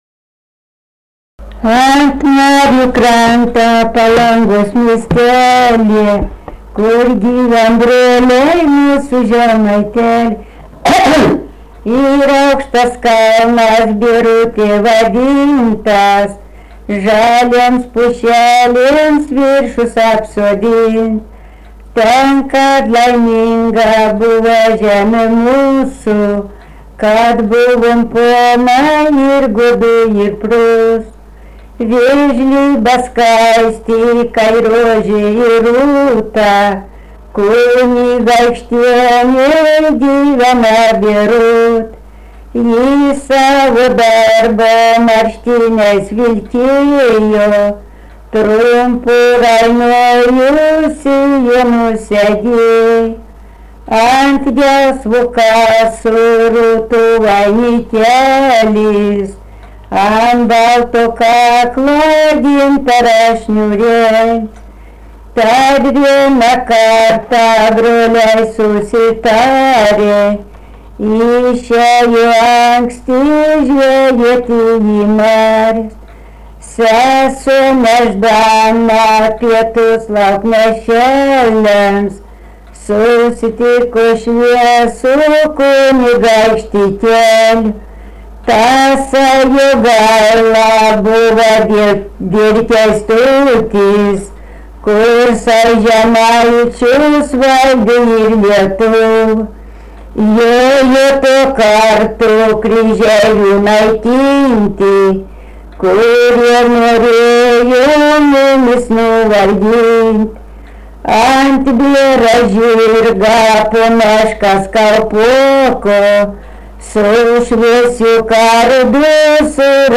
romansas
Vieštovėnai
vokalinis